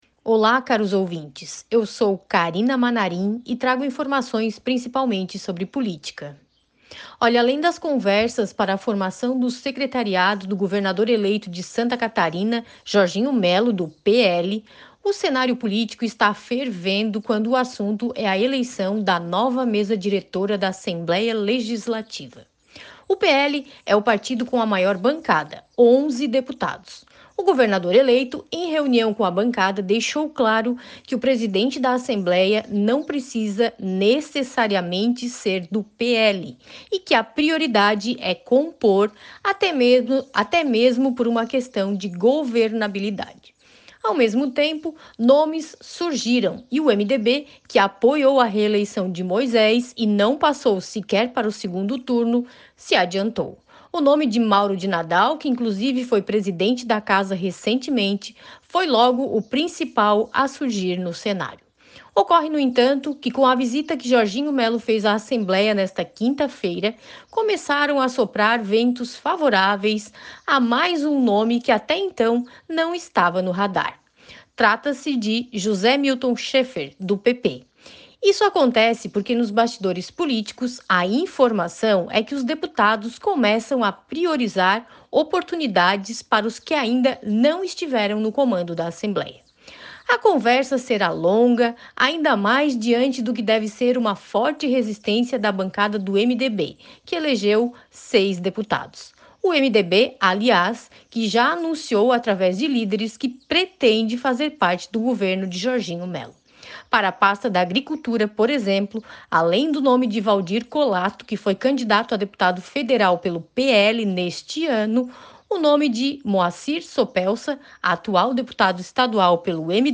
A jornalista destaca sobre alguns nomes de peso para compor a mesa diretora da Alesc para a próxima gestão